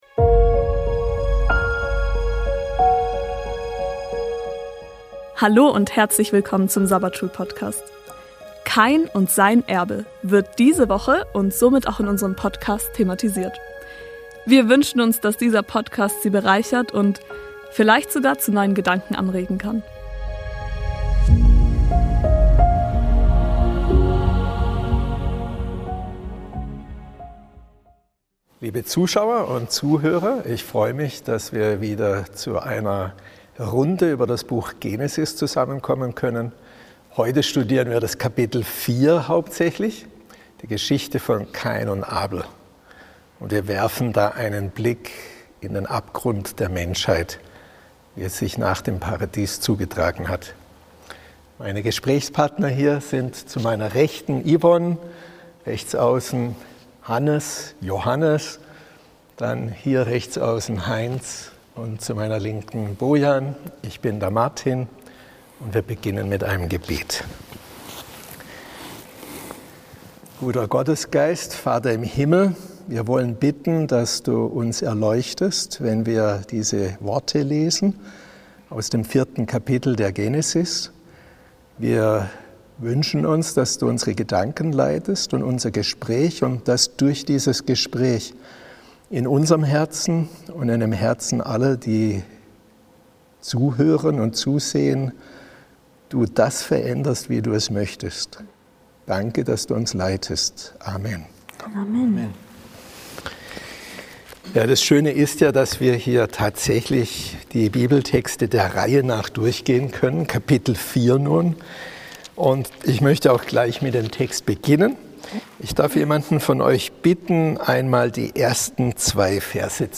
Hier hören sie das Sabbatschulgespräch aus Bogenhofen zur Weltfeldausgabe der Lektion der Generalkonferenz der Siebenten-Tags-Adventisten